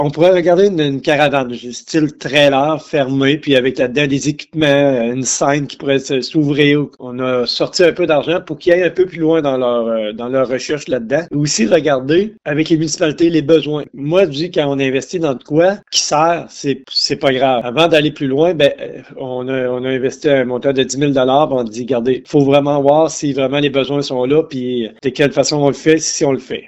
En entrevue, le préfet de la MRC, Mario Lyonnais, a donné davantage de détails sur le projet.